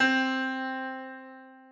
Harpsicord
c4.mp3